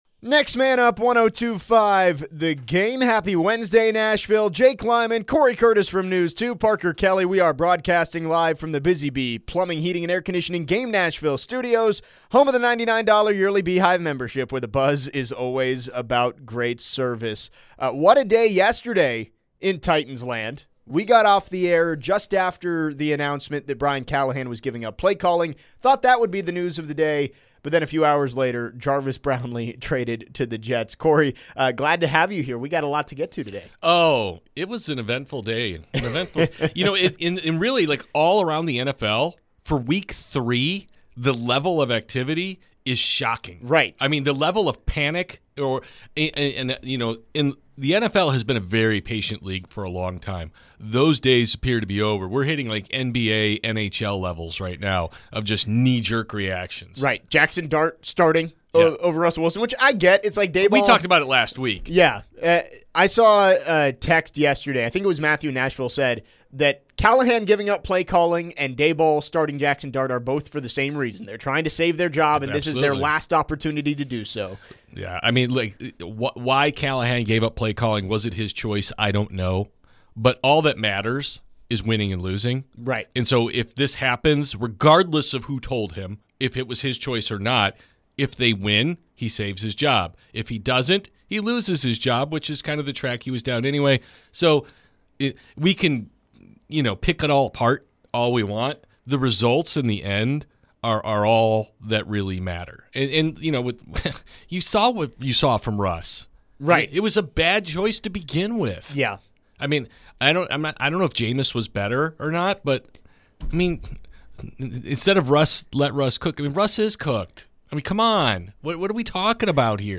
in studio today. They react to the news that the Titans traded cornerback Jarvis Brownlee Jr. to the New York Jets. Is the fire sale starting for the team?